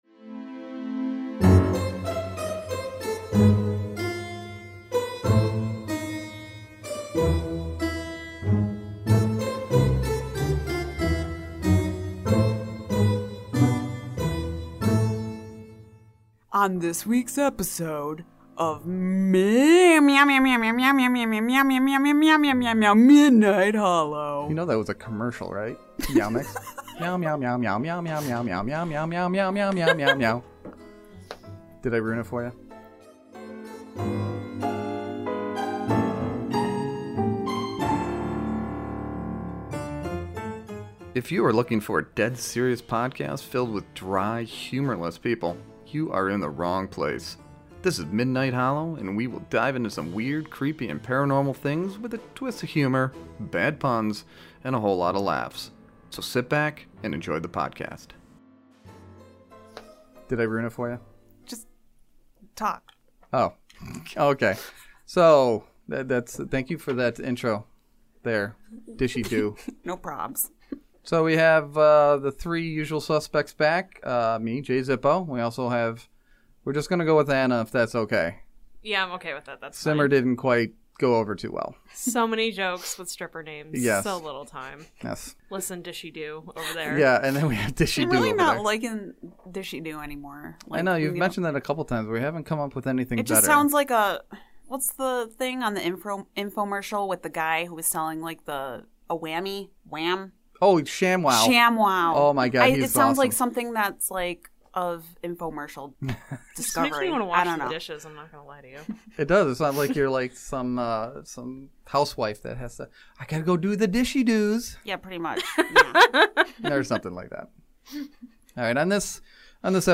Our topic of conversation is reincarnation, karma and a few news stories that include a woman getting slapped in the face by a mysterious flying fish.